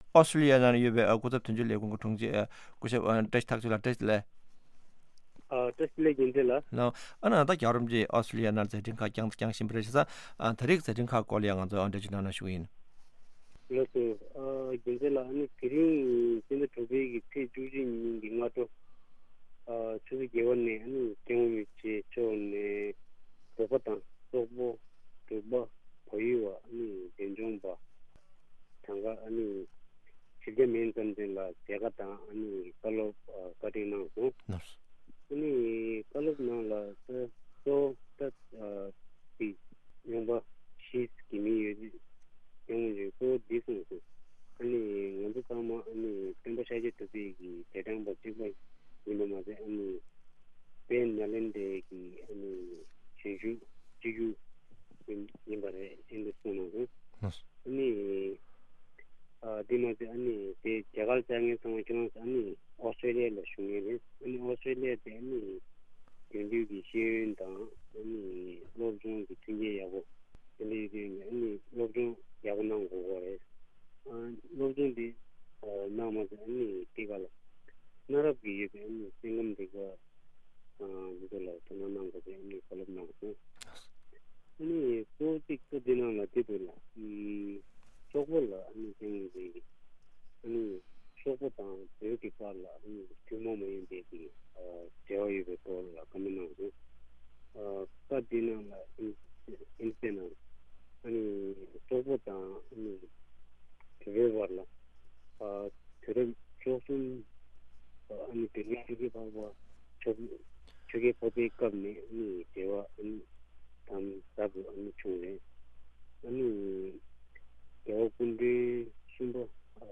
གནས་འདྲི་ཞུས་པའི་གནས་ཚུལ་ཞིག་གསན་གྱི་རེད།